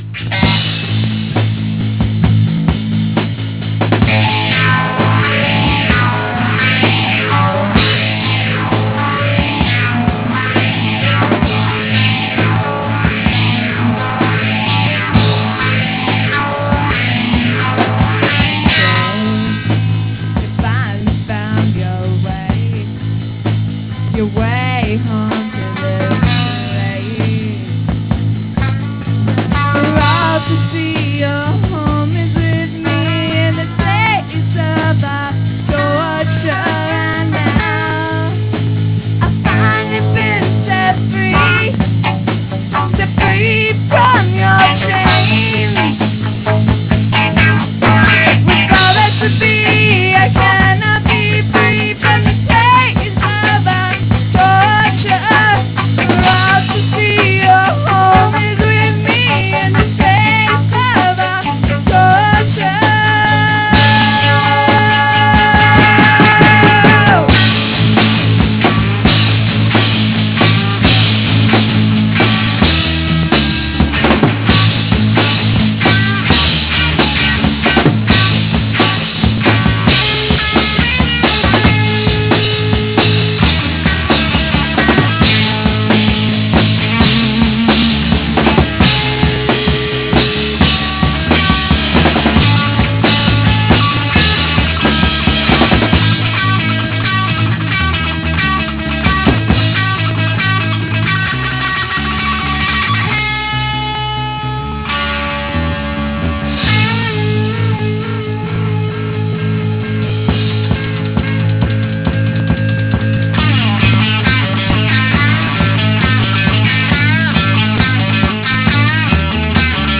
Vocals, Guitar, 12-String Guitar, Bass